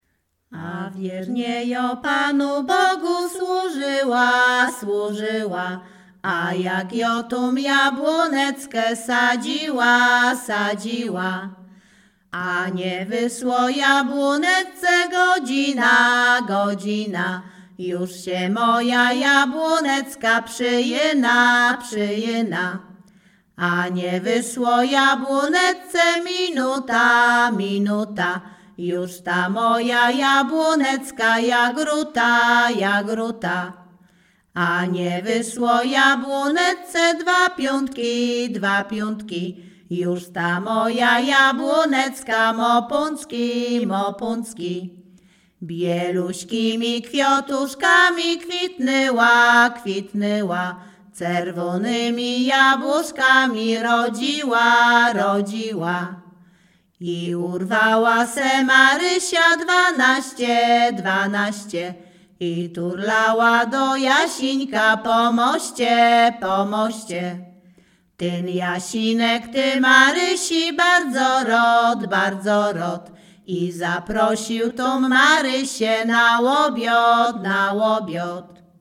Śpiewaczki z Chojnego
Sieradzkie
województwo łódzkie, powiat sieradzki, gmina Sieradz, wieś Chojne
Weselna